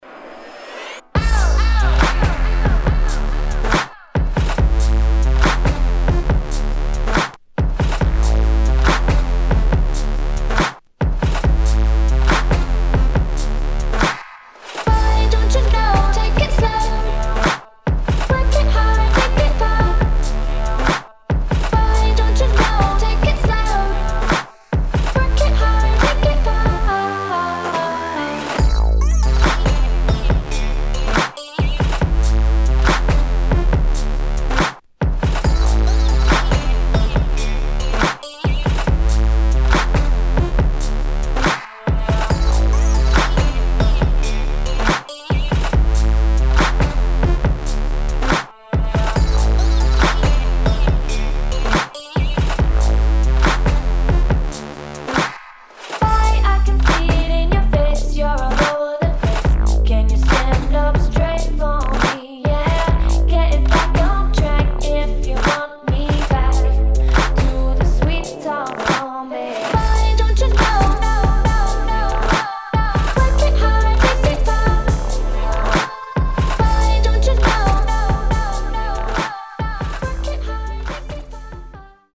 [ DUBSTEP ]